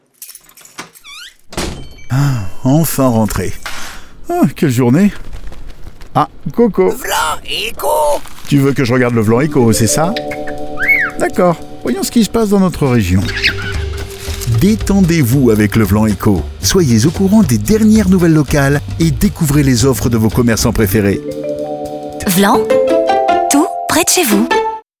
DES PUBS AUDIOS
Plus qu’un spot avec une bande sonore ordinaire, nous créons un univers sonore unique et distinct pour chaque spot avec des effets, des bruitages immersifs et une musique adaptée à votre production.